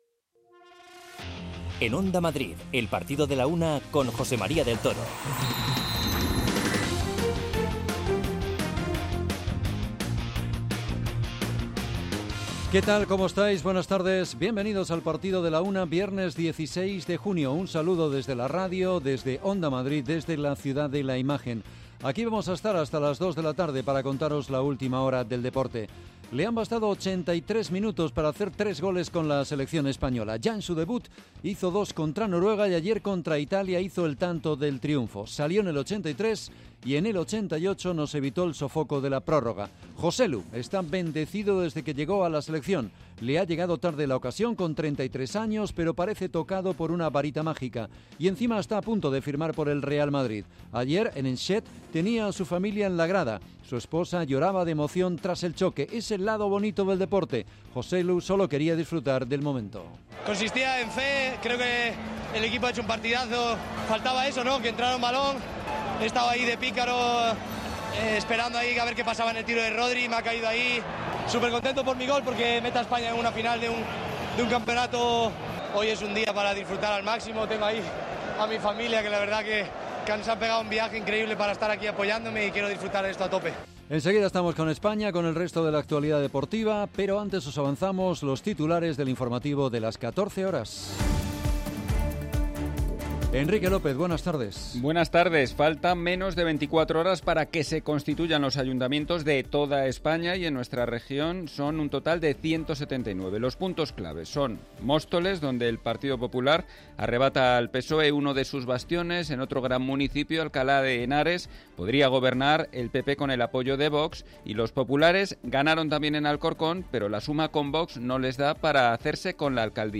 Comenzamos con el triunfo de España sobre Italia, triunfo que nos mete en la final de la Liga de las Naciones frente a Croacia. Analizamos la actuación de los nuestros y escuchamos en directo al goleador Joselu. España, a la final de la Liga de Naciones tras vencer a Italia 2-1 También escuchamos las reflexiones más interesantes de Luis de la Fuente y Rodri.